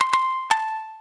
shamisen_c1c1a.ogg